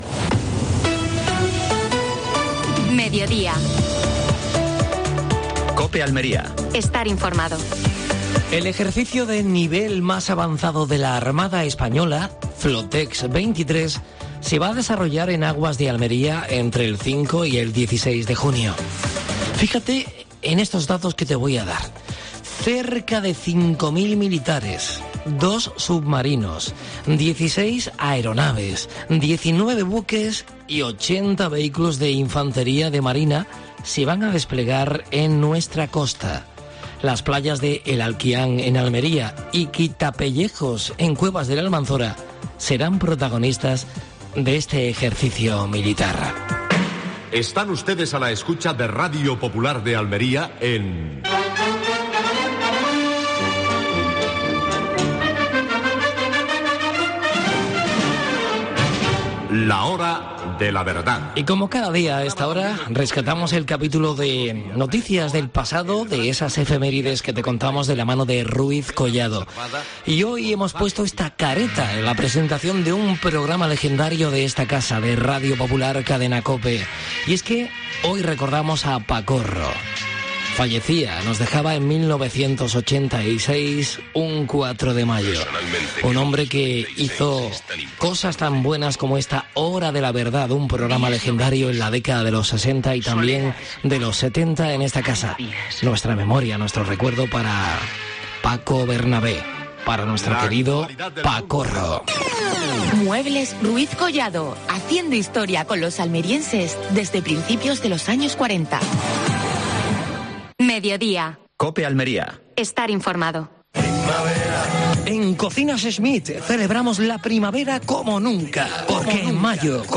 AUDIO: Actualidad en Almería. Entrevista a Ismael Torres (alcalde de Huércal de Almería).